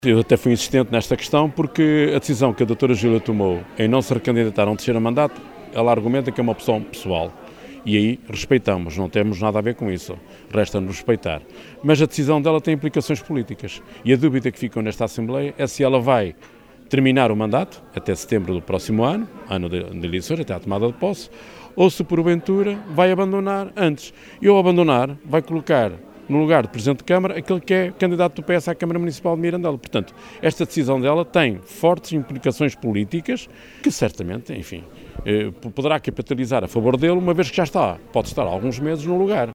No período antes da ordem do dia, Paulo Pinto questionou a autarca socialista, mas não obteve resposta: